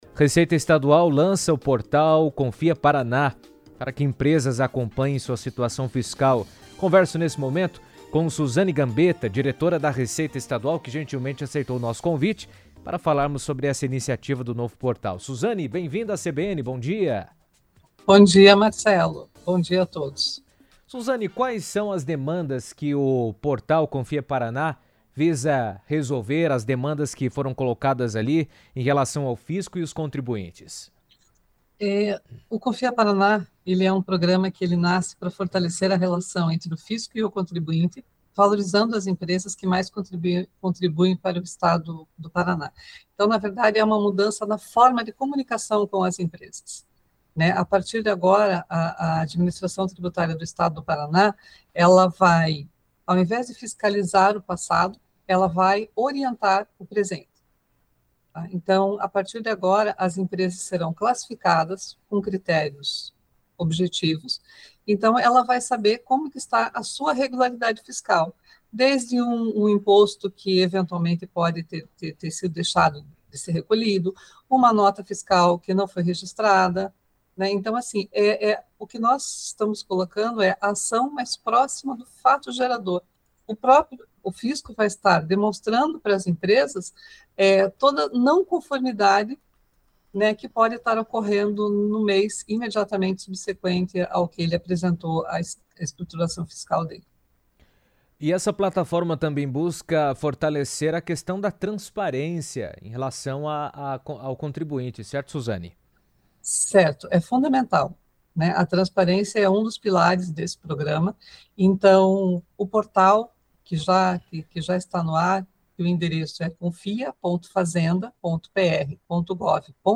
A Receita Estadual lançou o Portal Confia Paraná, uma nova ferramenta voltada a aproximar o Fisco dos contribuintes e facilitar o acompanhamento da situação fiscal das empresas. A plataforma permite a consulta de pendências, a verificação da regularidade e o incentivo à conformidade tributária de forma mais transparente e acessível. Em entrevista à CBN, Suzane Gambetta, diretora da Receita Estadual, destacou que a iniciativa busca fortalecer a relação com o setor produtivo e estimular boas práticas fiscais no Estado.